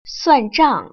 [suàn//zhàng] 쑤안장